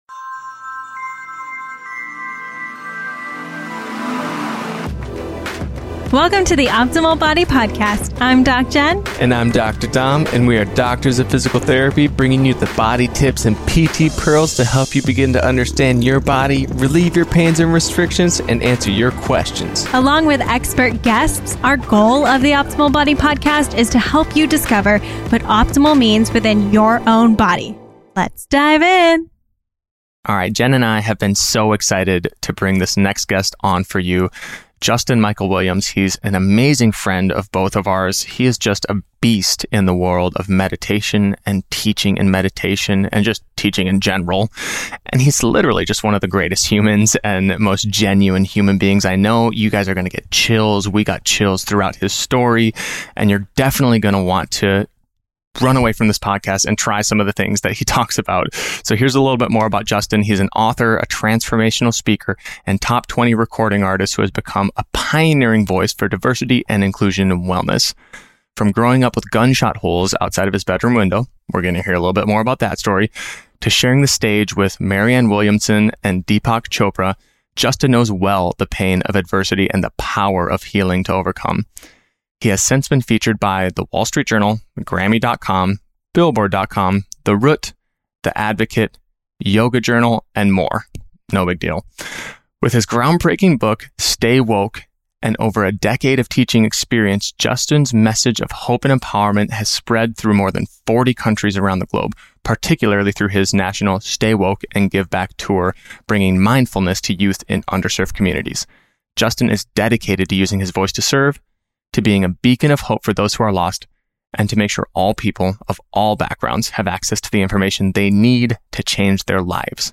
What You Will Learn in this interview